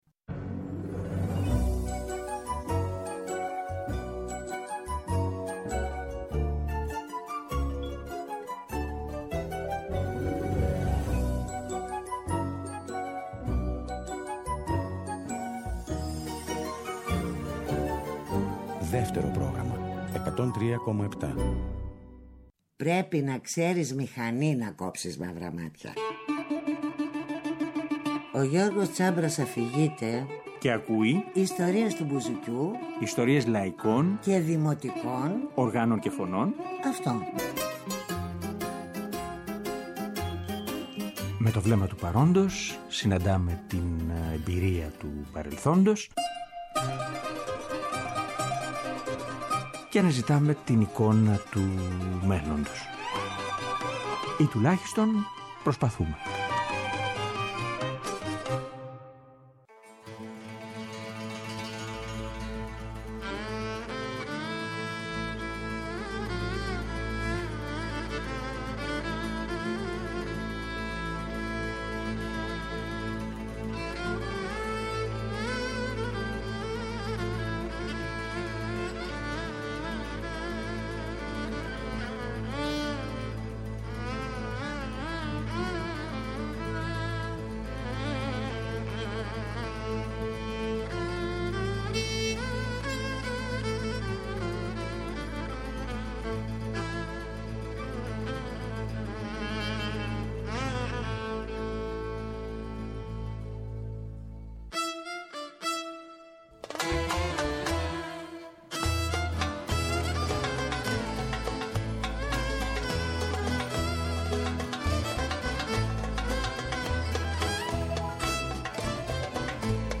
Μια ζωντανή ηχογράφηση που έγινε τον Ιούνιο του 2005, αλλά πρόσφατα είδε το φως της δημοσιότητας. Είναι μια «πρόβα» της Εστουδιαντίνας Νέας Ιωνίας Βόλου, για μια τιμητική βραδιά σε χώρο της πόλης – αφιερωμένη στους κορυφαίους σολίστες, με την παρουσία και την συμμετοχή τους.